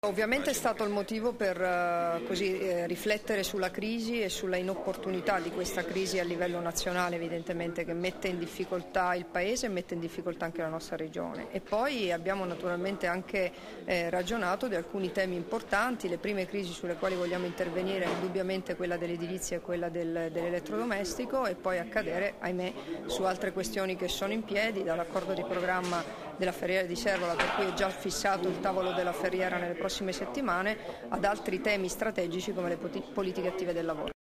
Dichiarazioni di Debora Serracchiani (Formato MP3) [552KB]
rilasciate a margine dell'incontro con i rappresentanti di CGIL, CISL, UIL e UGL, a Trieste il 1° ottobre 2013